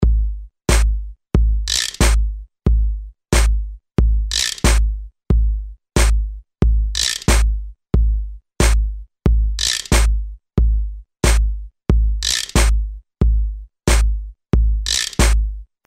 标签： 91 bpm Rap Loops Drum Loops 2.66 MB wav Key : Unknown